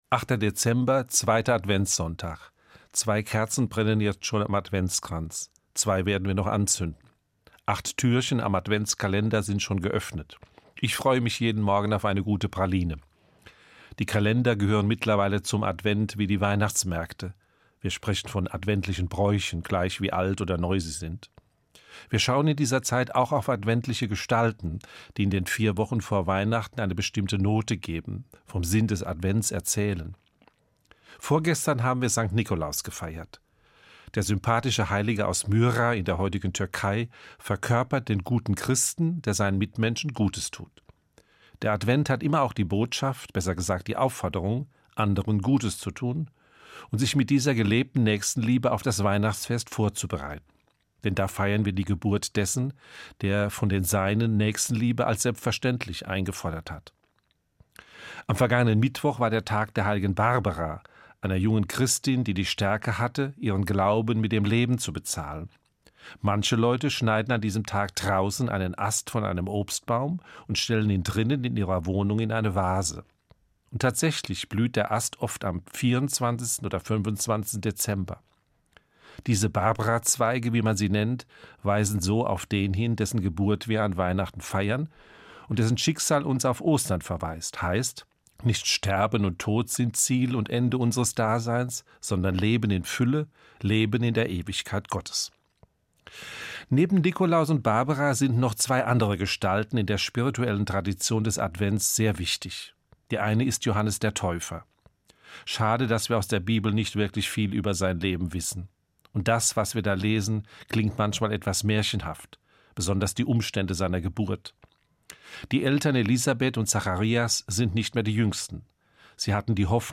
MORGENFEIER